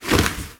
skeleton_kick.mp3